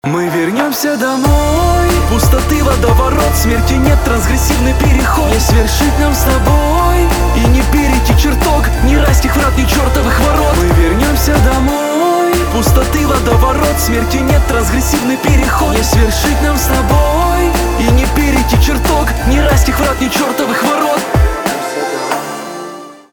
русский рэп , битовые , басы